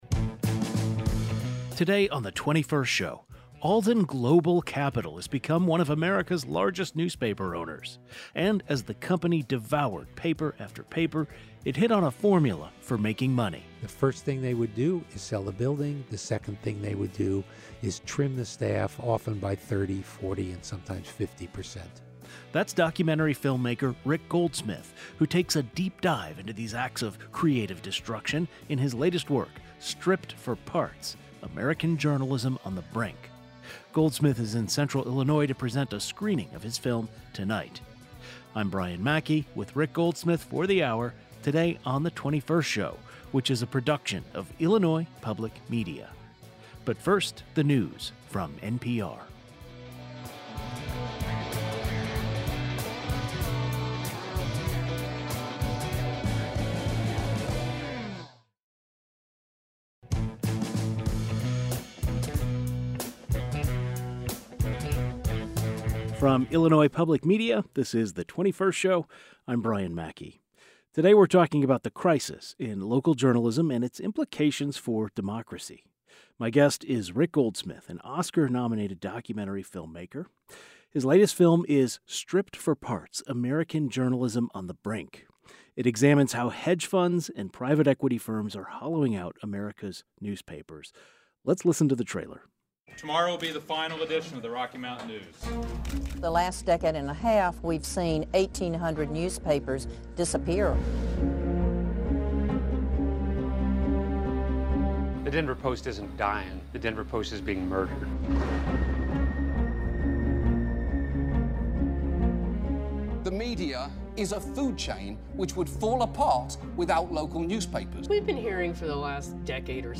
He joins the program today to discuss the causes of the crisis in local journalism.